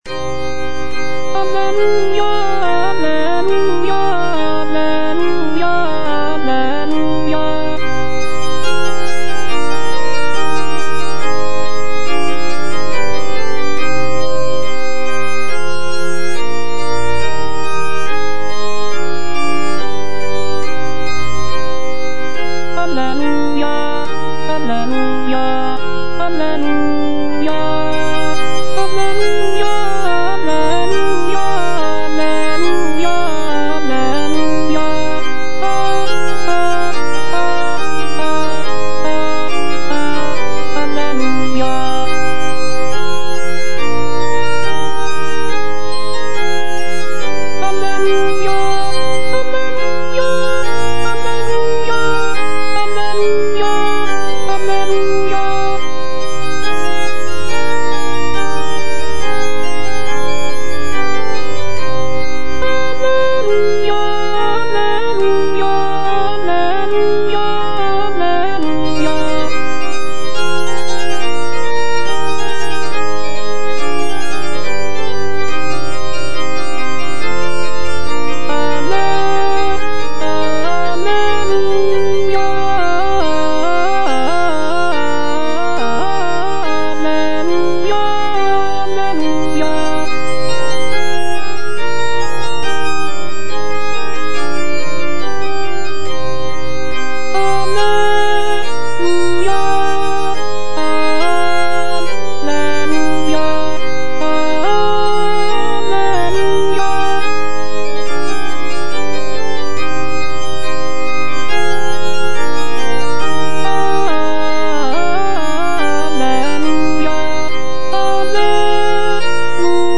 Alto (Voice with metronome) Ads stop
sacred vocal work